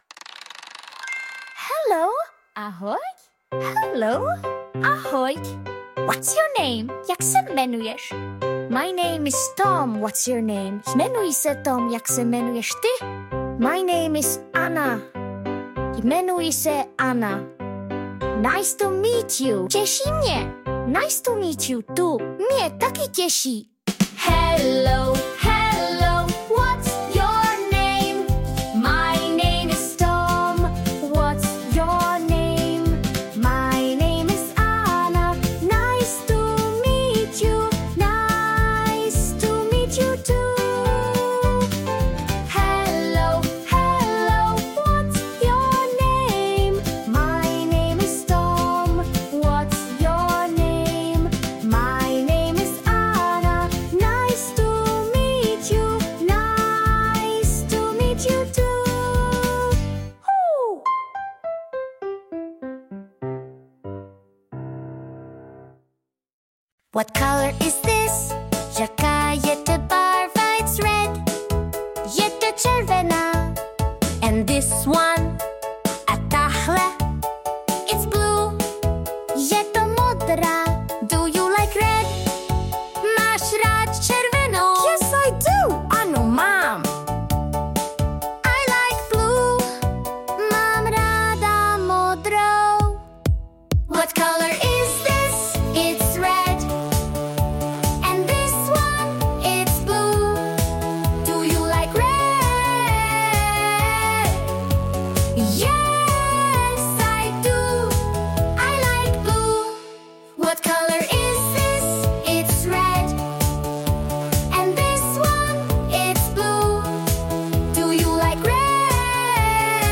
Učení angličtiny pro děti pomocí písniček